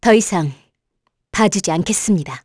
voices / heroes / kr
Glenwys-Vox_Skill4_kr.wav